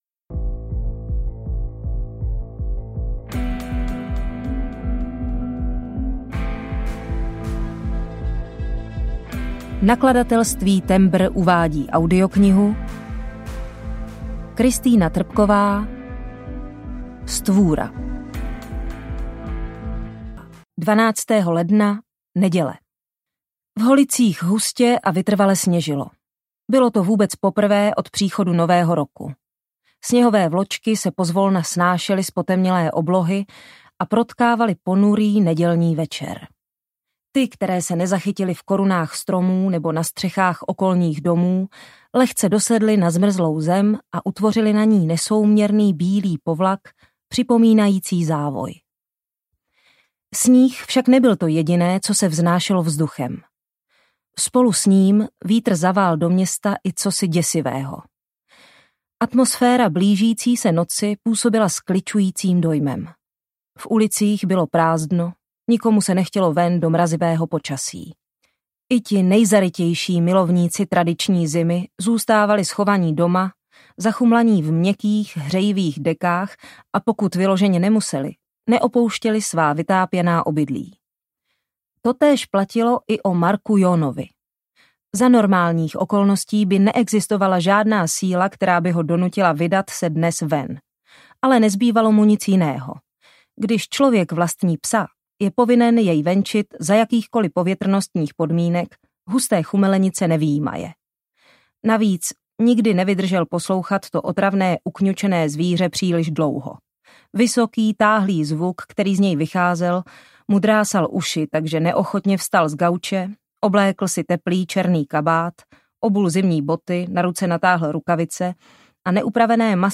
Stvůra audiokniha
Ukázka z knihy
• InterpretZuzana Kajnarová